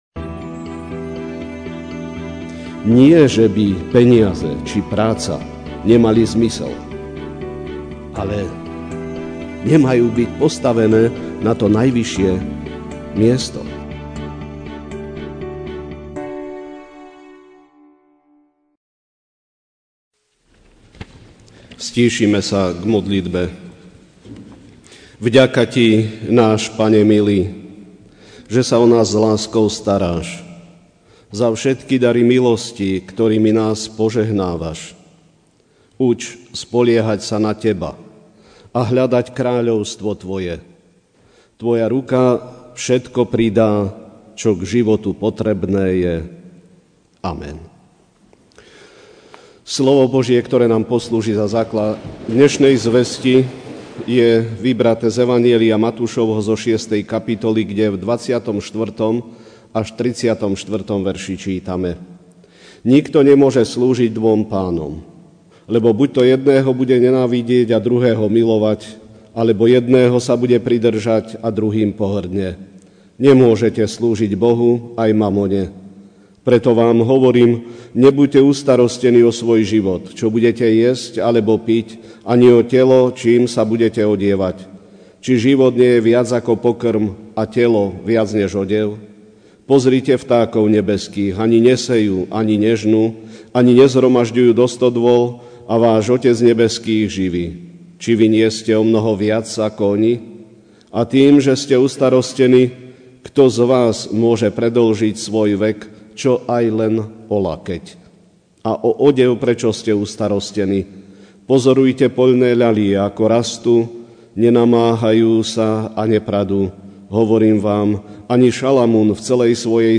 Ranná kázeň: Nenechajme sa premôcť slabosťami ( Matúš 6, 24-34)Nikto nemôže slúžiť dvom pánom;lebo buďto jedného bude nenávidieť a druhého milovať, alebo jedného sabude pridŕžať a druhým pohrdne.